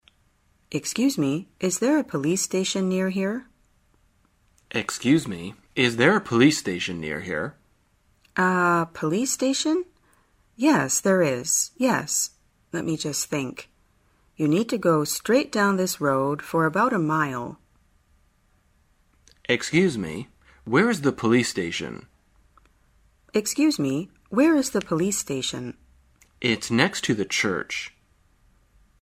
旅游口语情景对话 第346天:如何询问怎样去警察局